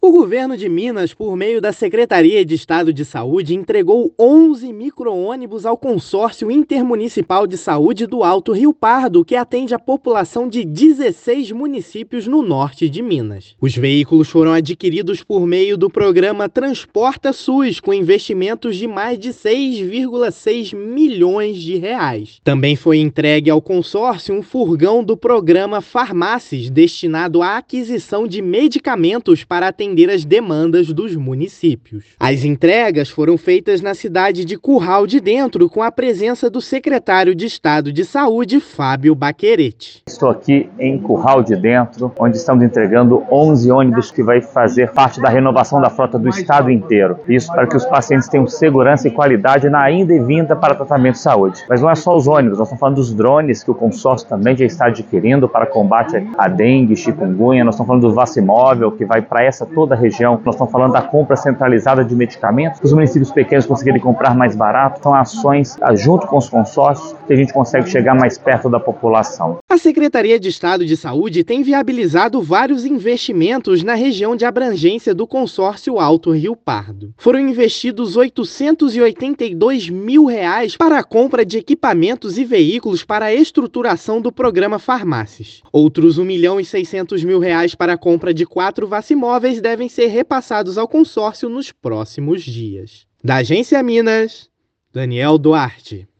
Veículos vão atender moradores de 16 municípios da região Norte do Estado. Ouça a matéria de rádio: